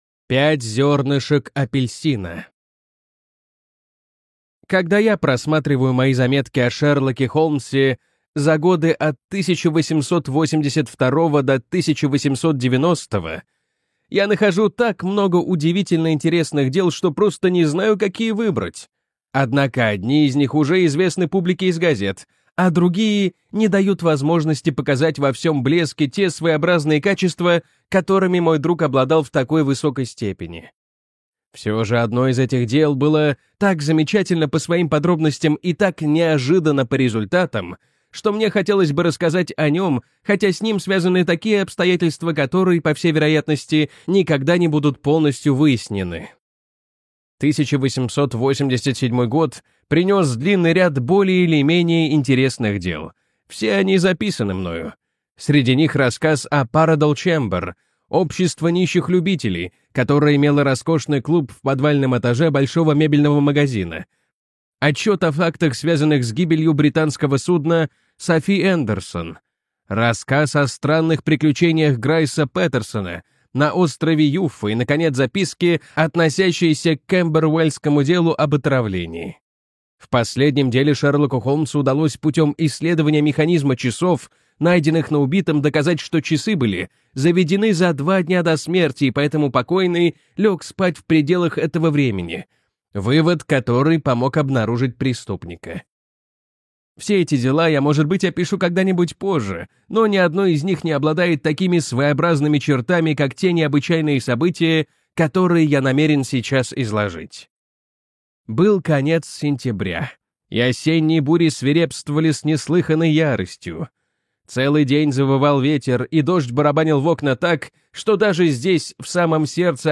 Пять зернышек апельсина - аудио рассказ Конан Дойла. Тайна смертей семьи Опеншоу.